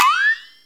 FLEXATONE.wav